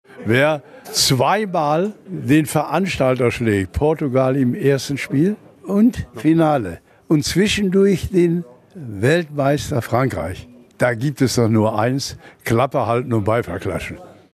Sportlich haben die Fans einiges zu erwarten, versprach Rehhagel im Radio Essen Interview.